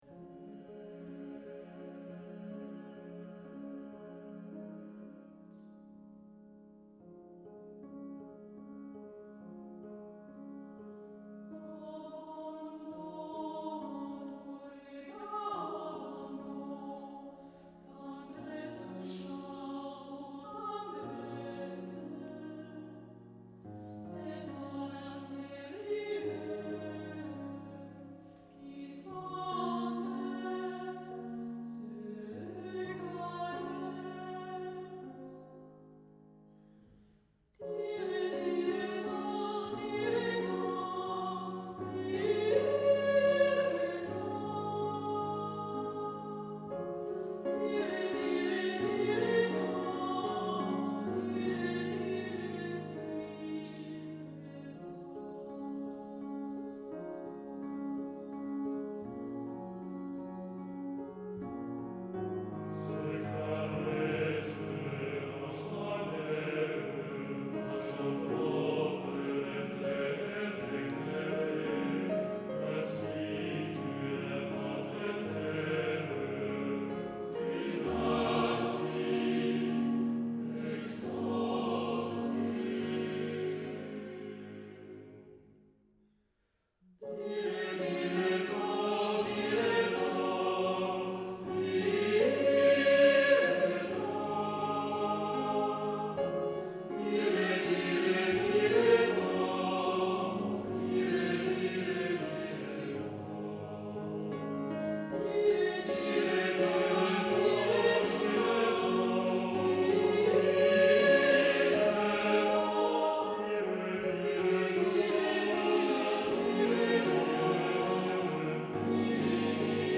Choeur Mixte (SATB) et Piano